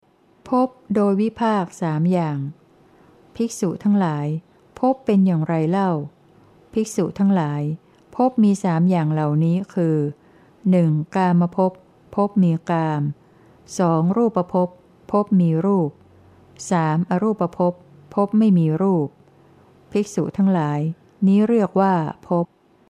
เสียงอ่าน